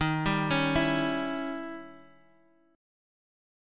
Listen to D#7b9 strummed